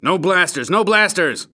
Unidentified Barons Hed bartender asking for a lack of blaster use in his bar
BaronsHed_Bartender_No_Blasters.ogg